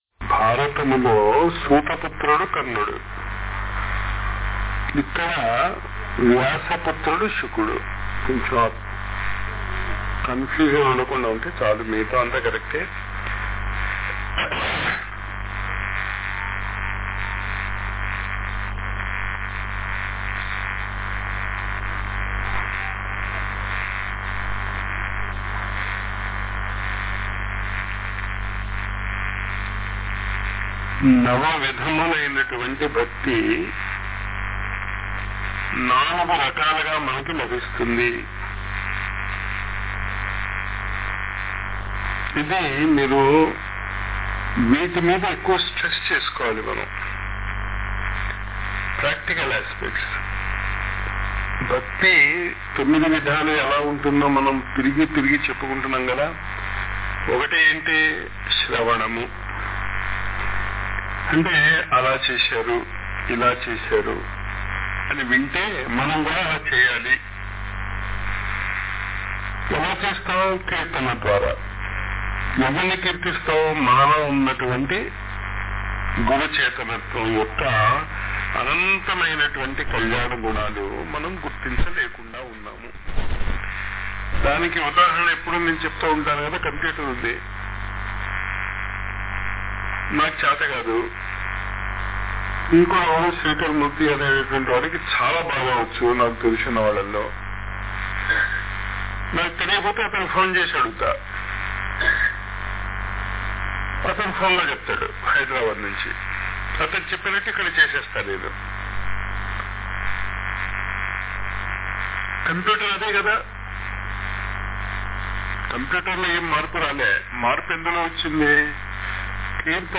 శ్రీ మద్భాగవతముపై ఇచ్చిన ప్రవచనములు